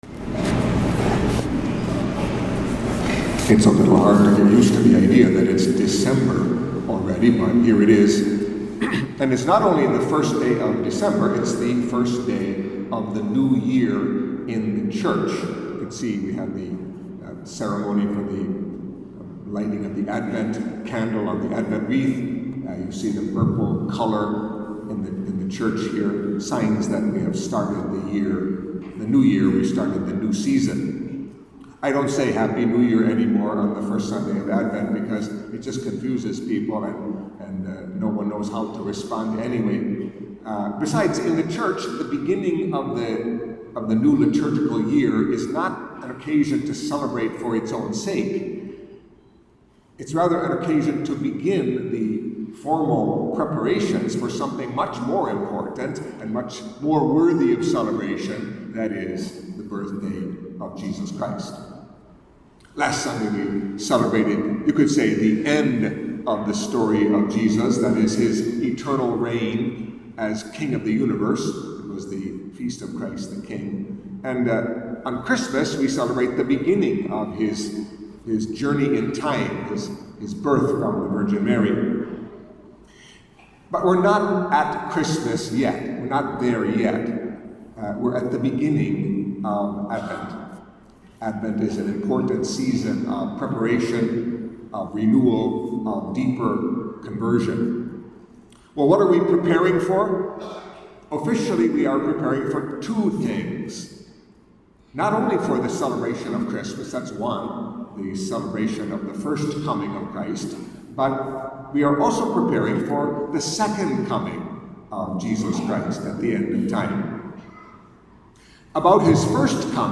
Catholic Mass homily for the First Sunday of Advent